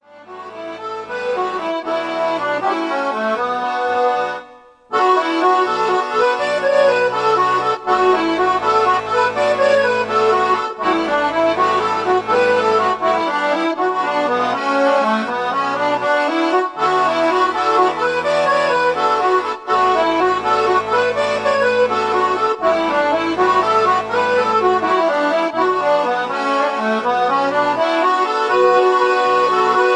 The Pibroch followed by its Largamente